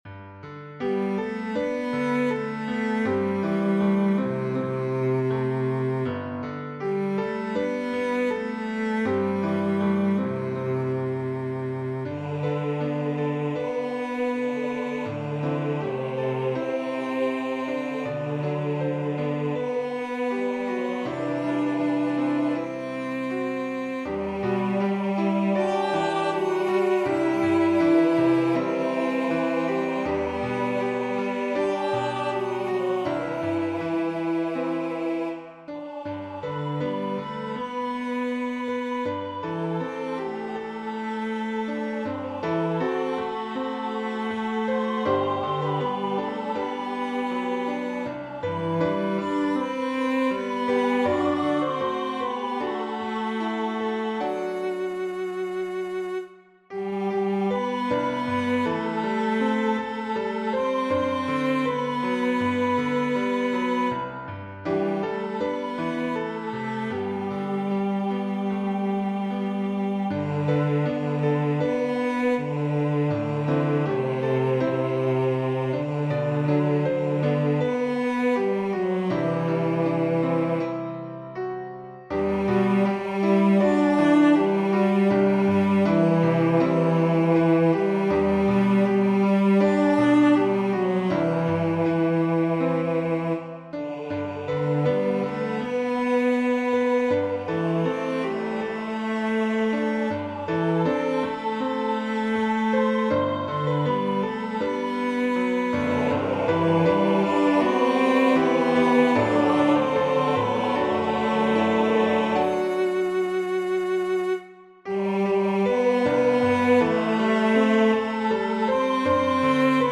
Voicing/Instrumentation: SATB
Cello Optional Obbligato/Cello Accompaniment Piano